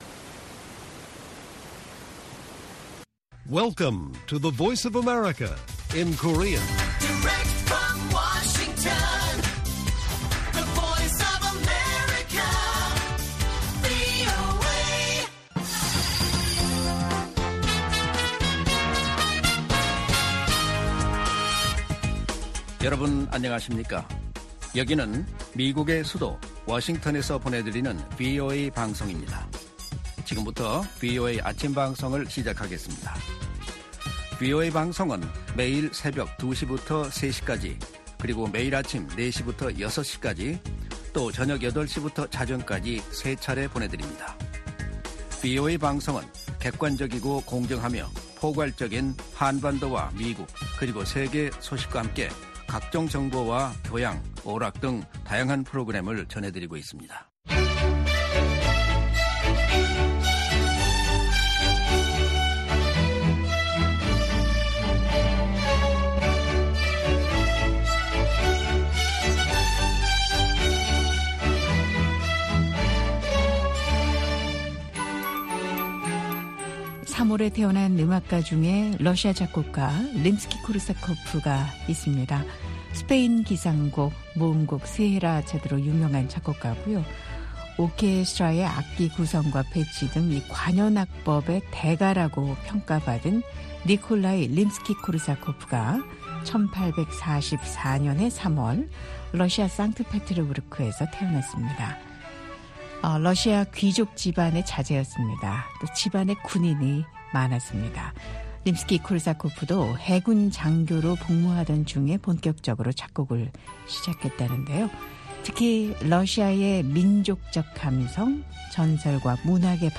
VOA 한국어 방송의 일요일 오전 프로그램 1부입니다. 한반도 시간 오전 4:00 부터 5:00 까지 방송됩니다.